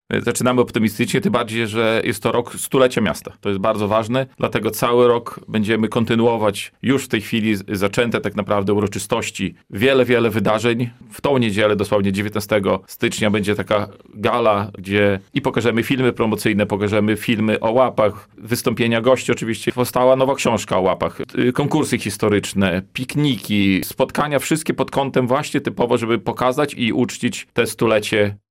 To dla nas ważny czas, dlatego chcemy to należycie  uhonorować – mówił dziś na naszej antenie burmistrz Krzysztof Gołaszewski: